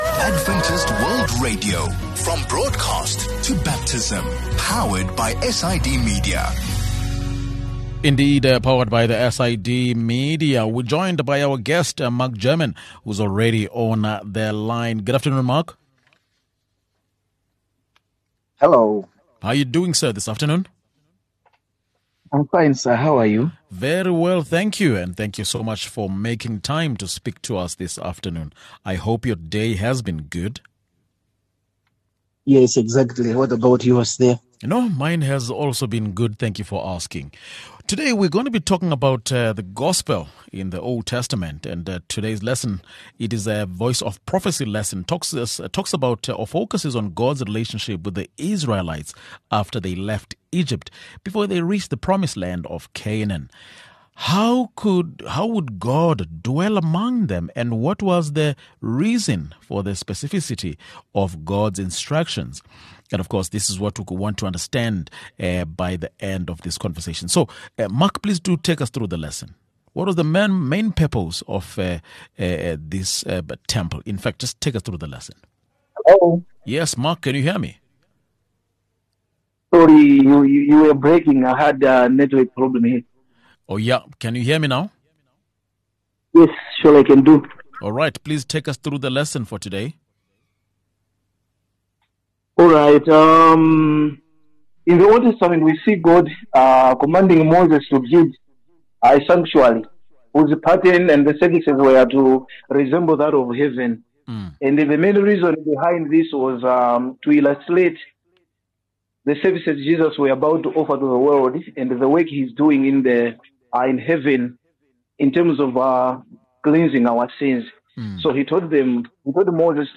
22 Apr VOP Lesson | The Gospel in the Old Testament